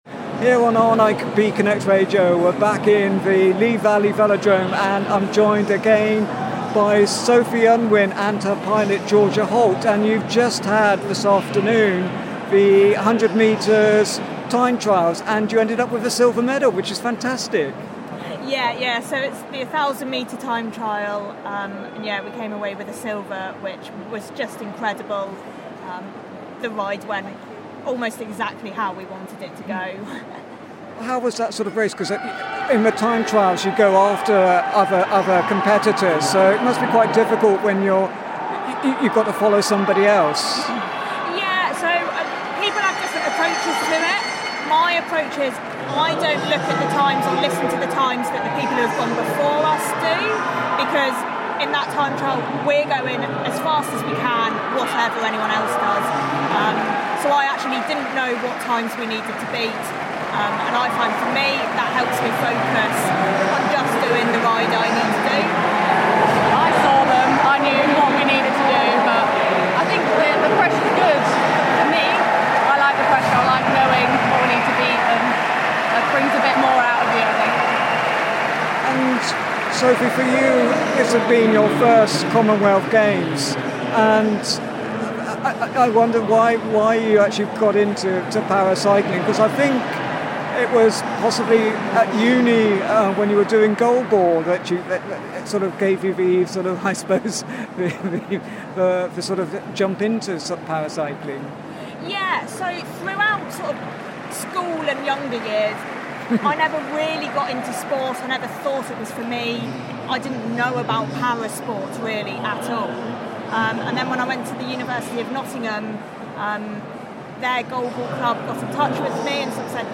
More from the Commonwealth Games at the Lee Valley Velodrome